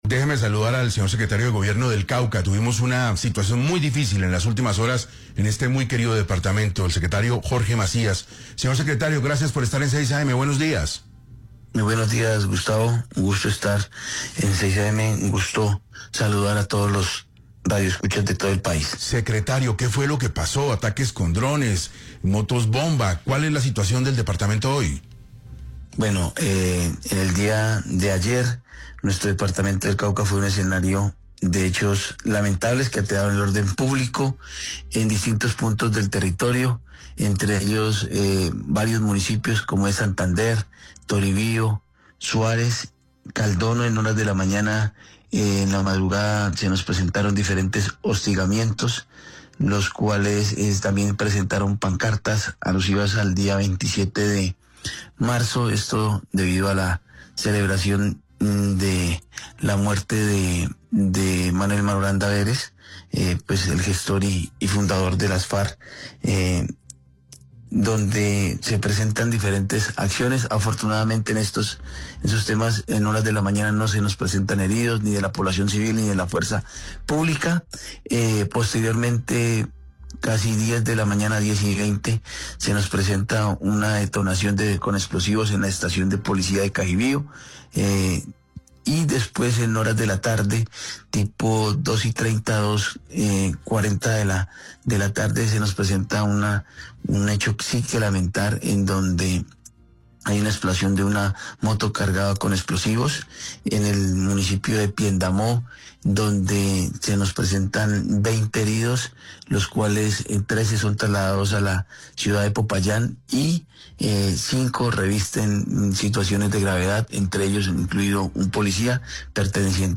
Jorge Macías, secretario de Gobierno del Cauca, habló en 6AM de Caracol Radio sobre esta situación, asegurando que es lamentable lo que vive el departamento, pero reiterando que la complejidad del conflicto va más allá de lo coyuntural, pidiendo mayor inversión social para la región.